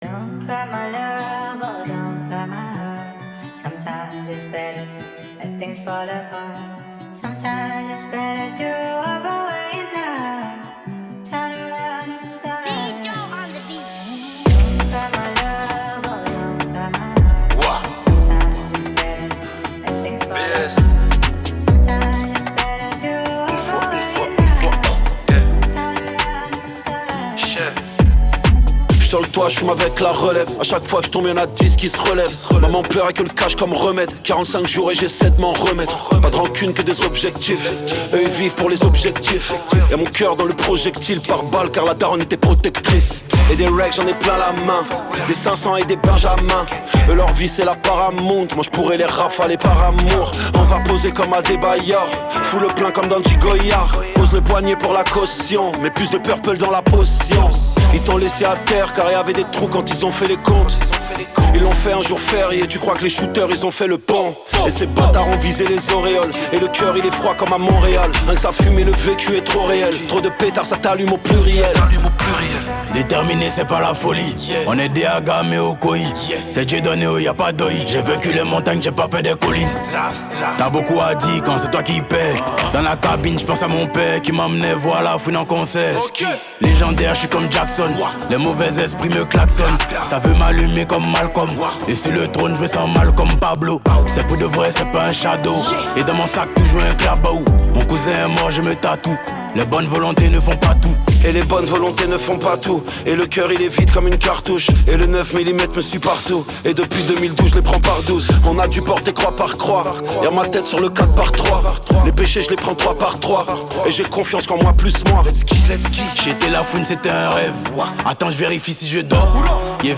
french rap, french r&b Écouter sur Spotify